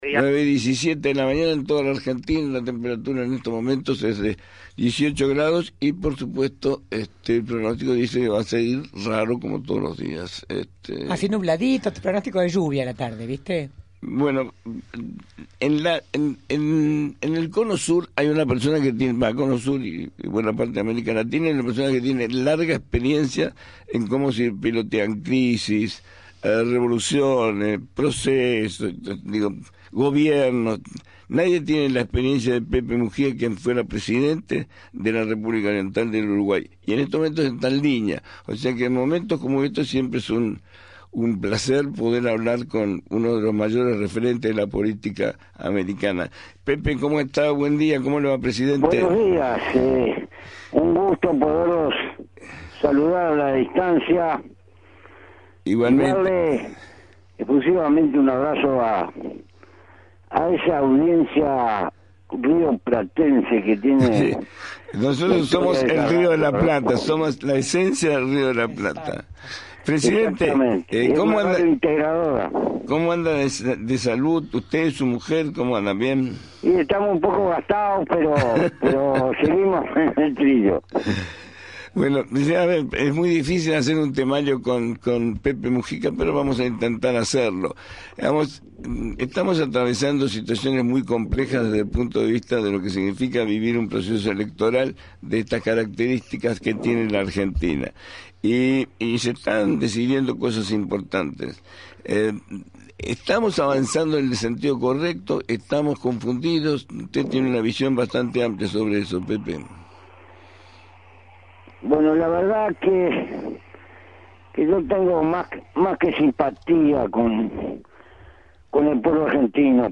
En dialogo con AM 550 el expresidente dijo : …